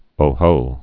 (ō-hō)